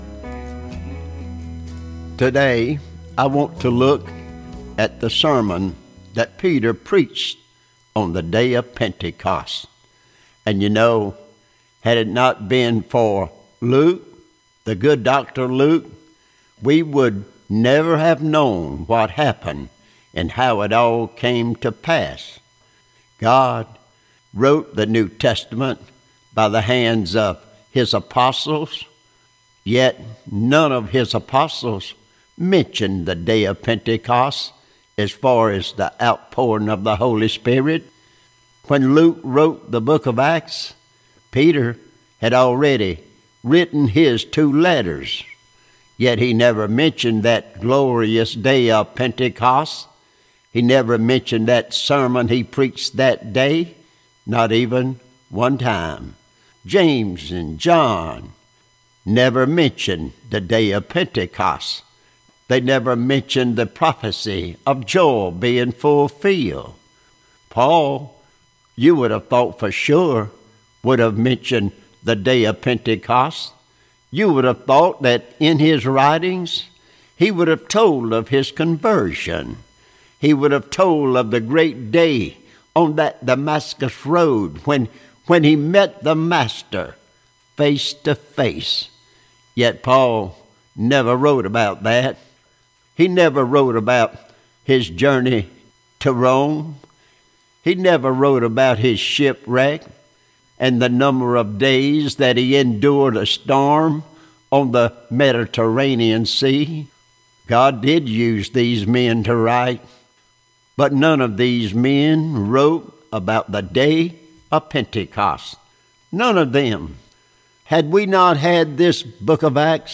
4_30_20_ Peters Sermon